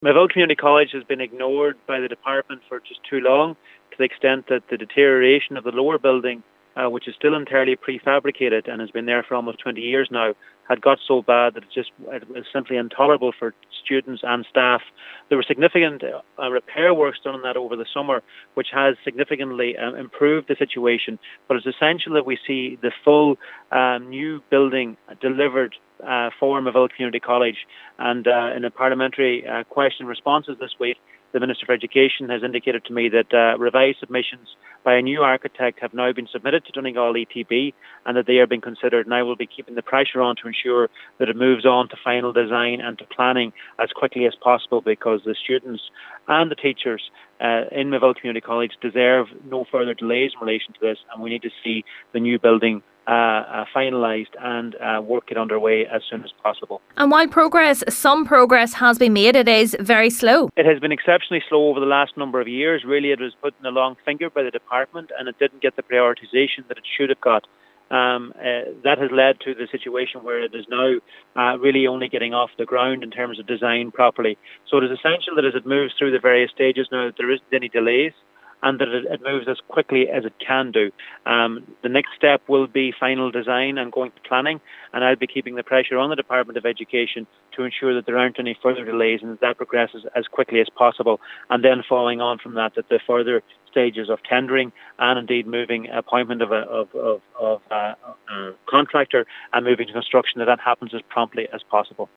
Deputy McConalogue has pledged to keep the matter high on his agenda: